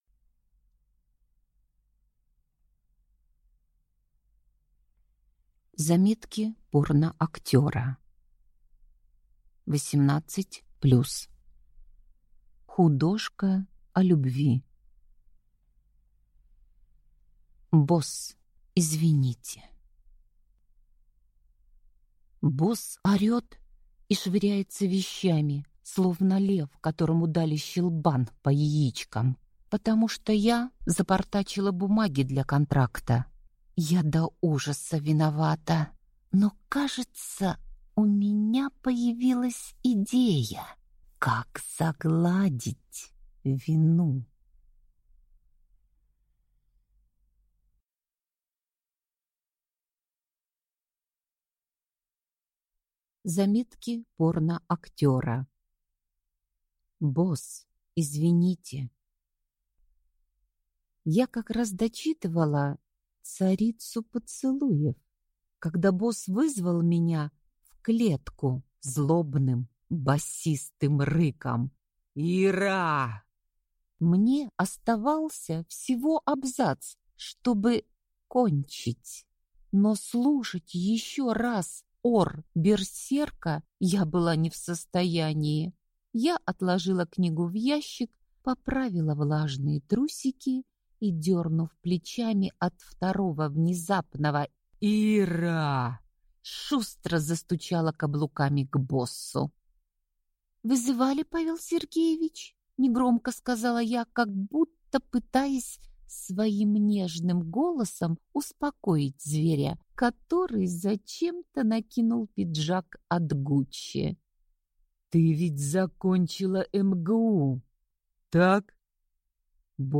Aудиокнига Босс, извините!